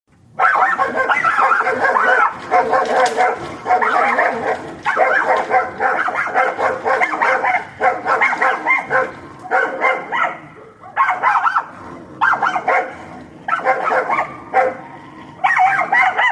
Другие рингтоны по запросу: | Теги: собаки, лай
Категория: Различные звуковые реалтоны